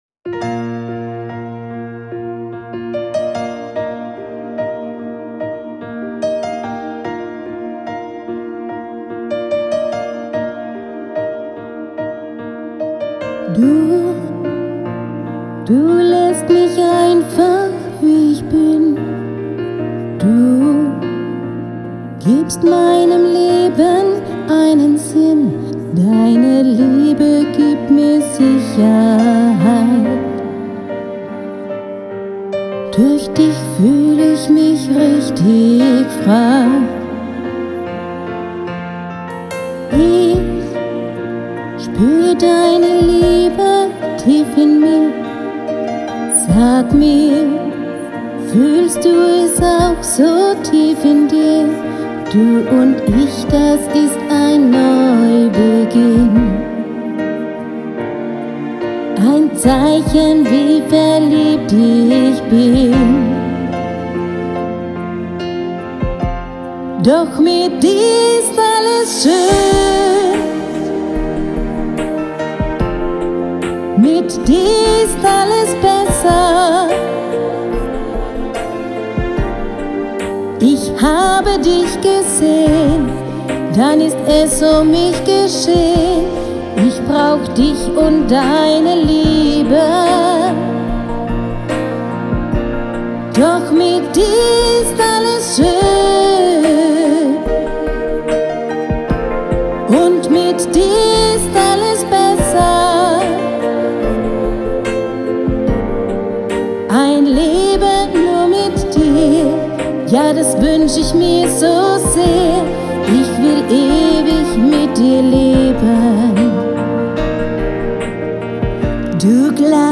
professioneller Livegesang